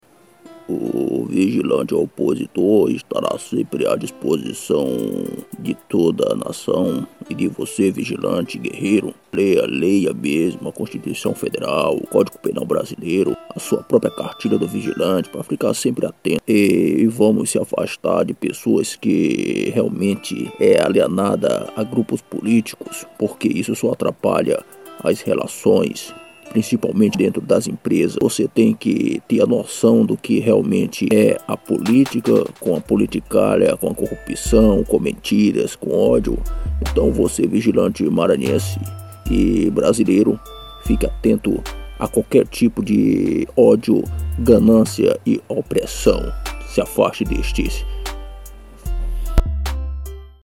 EstiloJingles / Spots